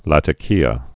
(lătə-kēə)